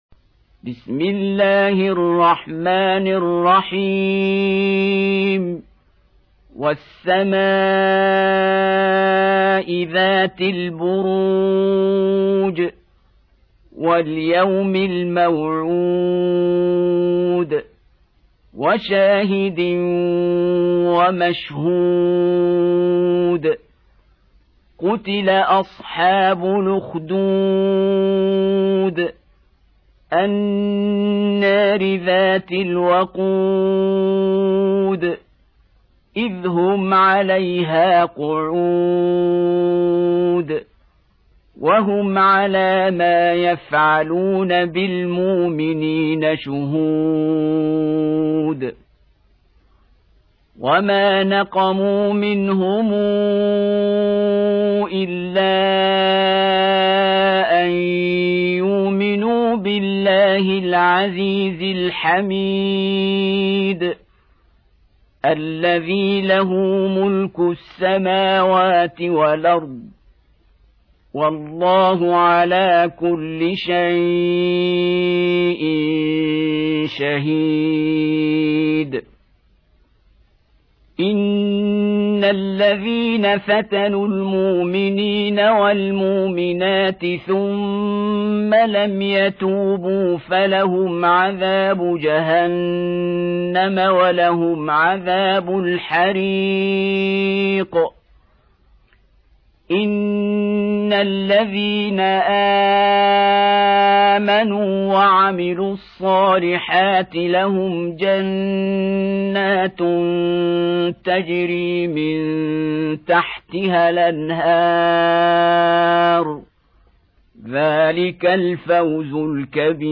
85. Surah Al-Bur�j سورة البروج Audio Quran Tarteel Recitation
Surah Repeating تكرار السورة Download Surah حمّل السورة Reciting Murattalah Audio for 85.